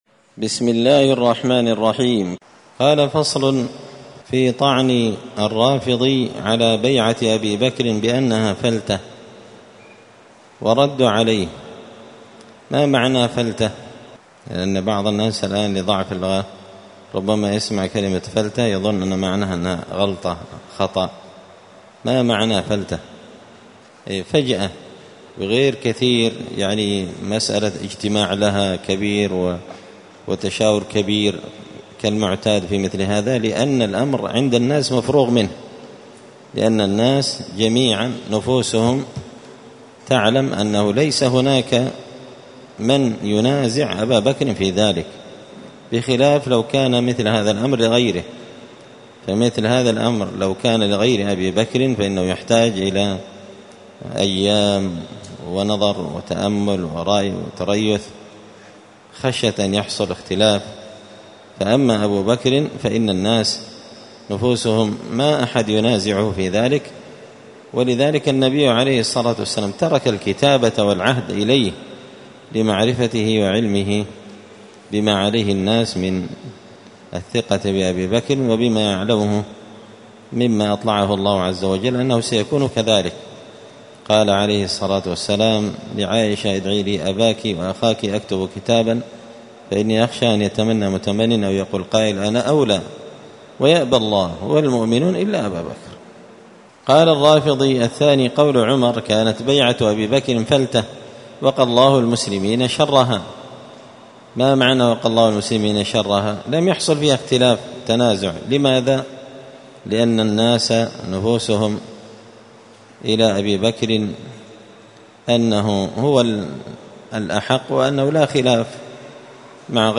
الأربعاء 12 ربيع الأول 1445 هــــ | الدروس، دروس الردود، مختصر منهاج السنة النبوية لشيخ الإسلام ابن تيمية | شارك بتعليقك | 8 المشاهدات
مسجد الفرقان قشن_المهرة_اليمن